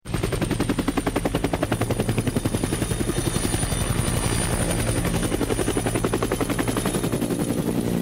helicopter.mp3